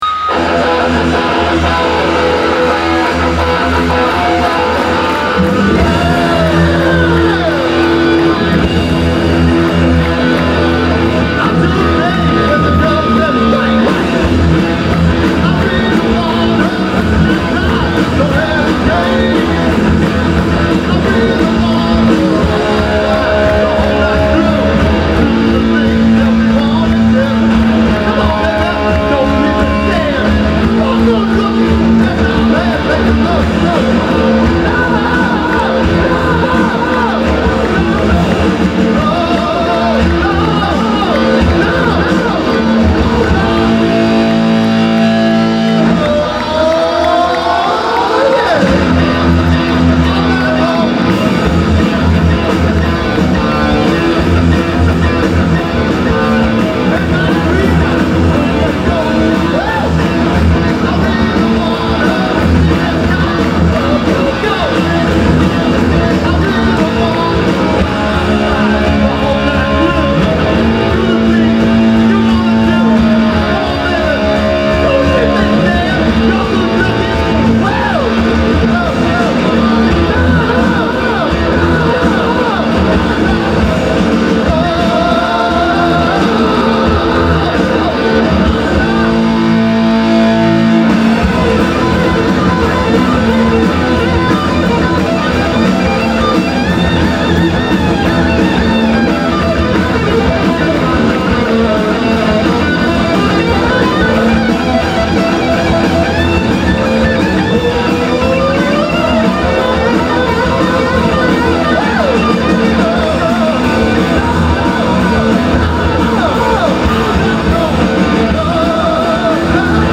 rhythm guitar, vocals
drums, vocals